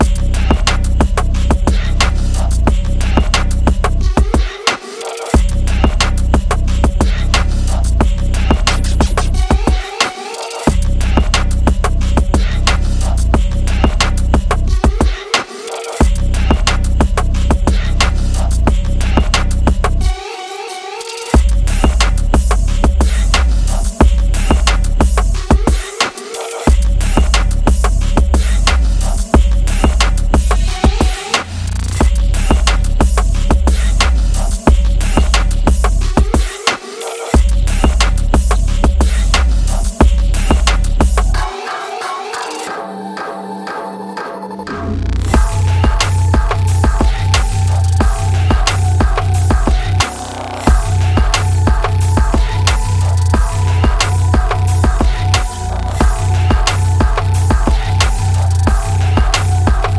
forward-thinking atmospheric production
five bass-fuelled tracks